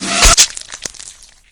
spear_hit.ogg